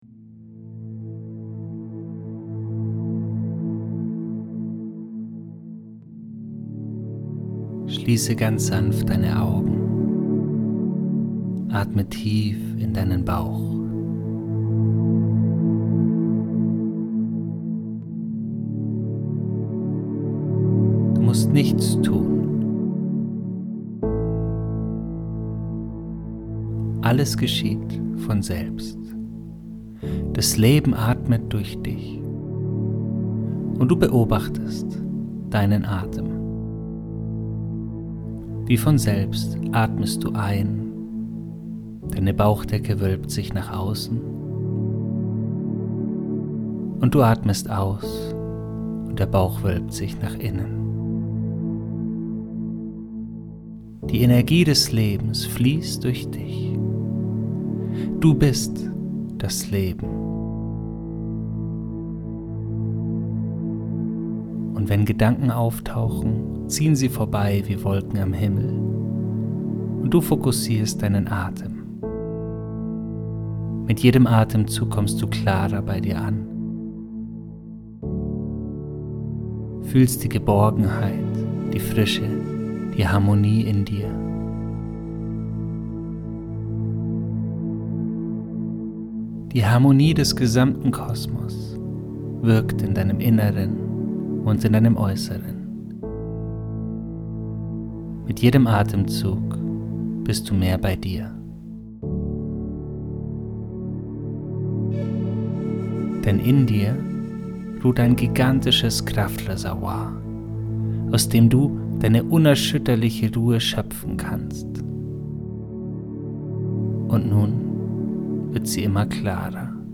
Meditation Verbundenheit